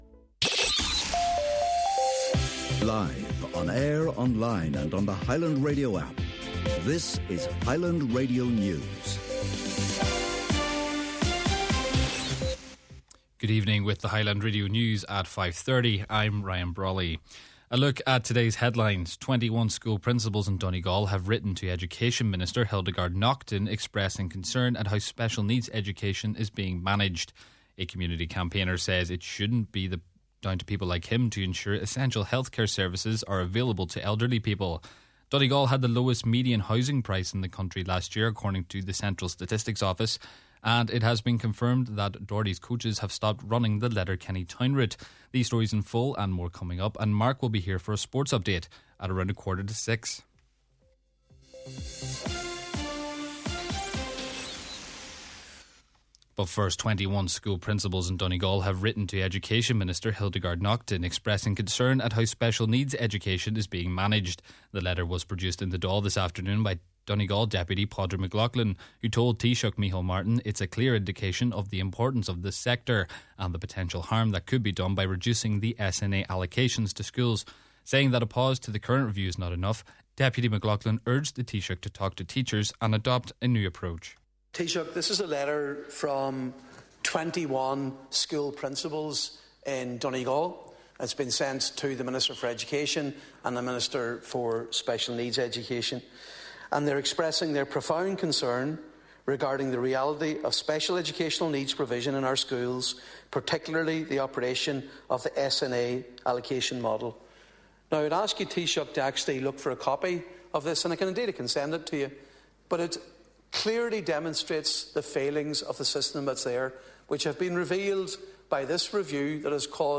Main Evening News, Sport and Obituary Notices – Wednesday, February 18th